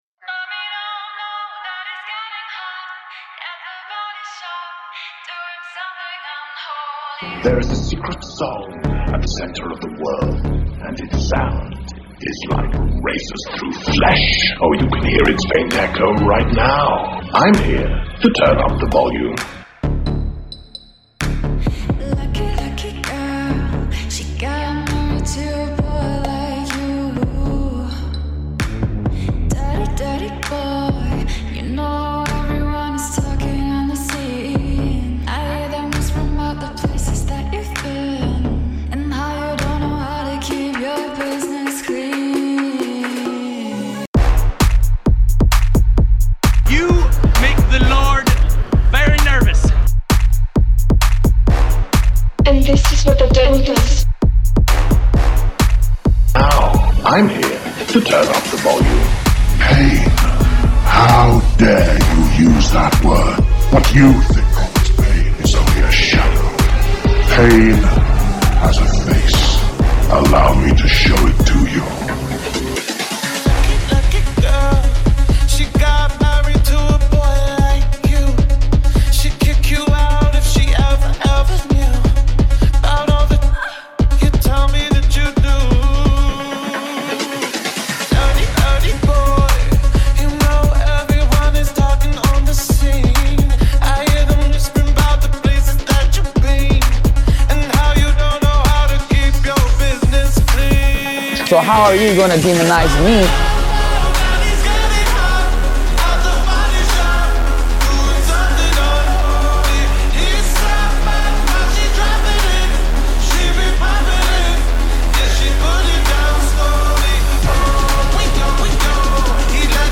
New remix for a new era